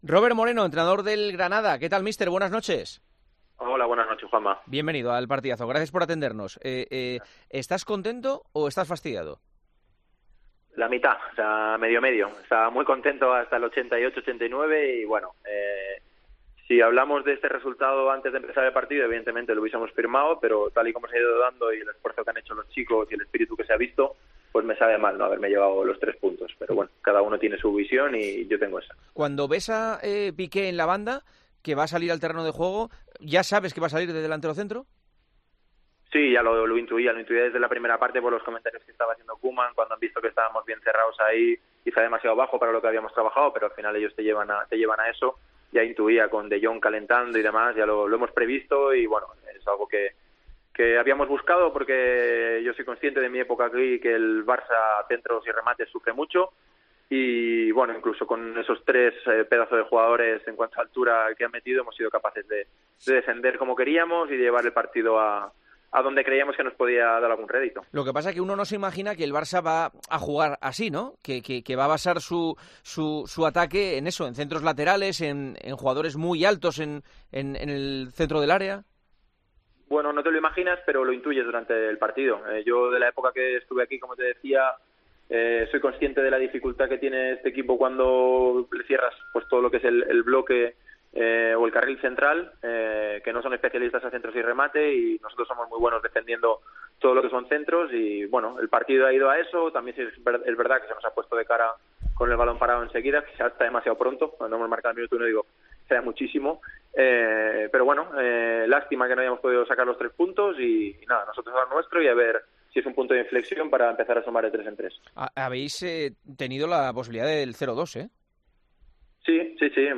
El entrenador del Granada afirmó, en El Partidazo de COPE, que el empate en el Camp Nou le deja "con sabor agridulce".